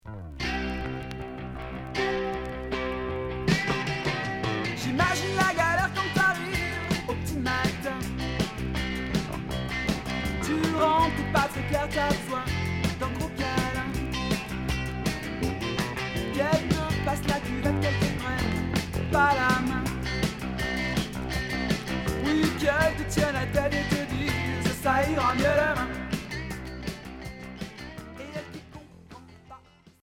Rock Unique 45t retour à l'accueil